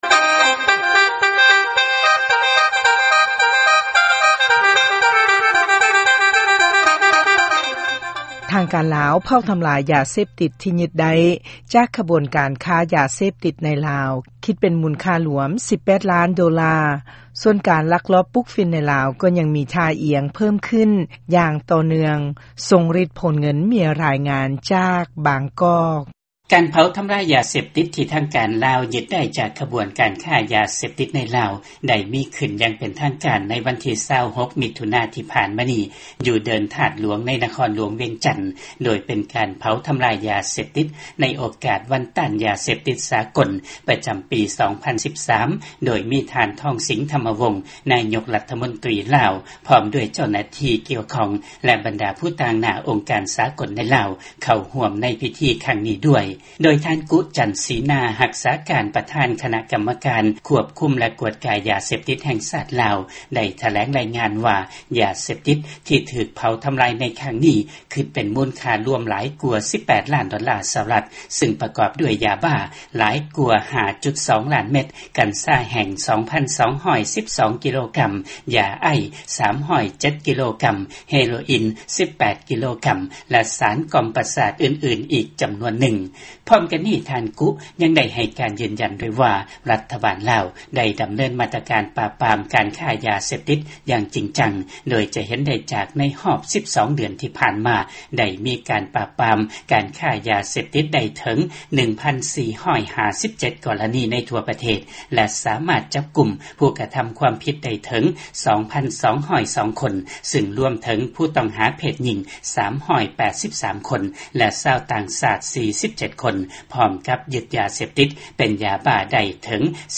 ຟັງລາຍງານການຈູດຢາເສບຕິດຢູ່ລາວ